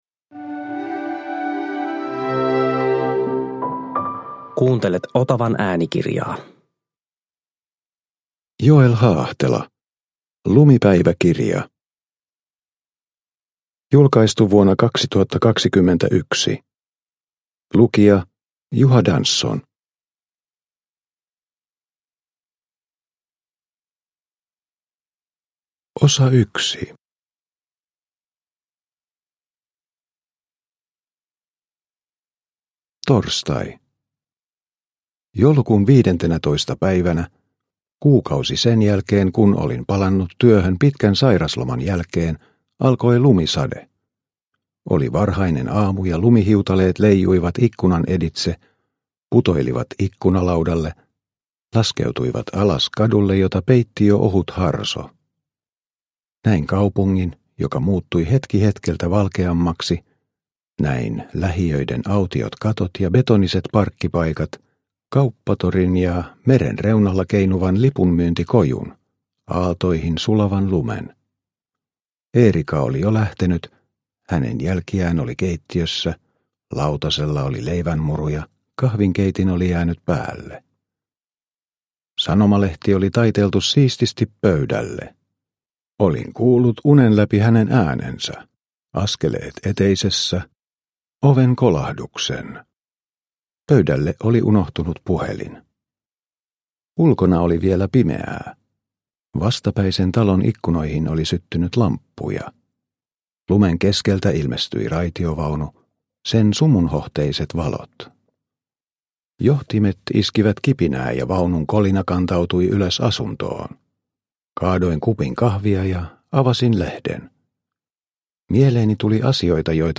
Lumipäiväkirja – Ljudbok – Laddas ner
Produkttyp: Digitala böcker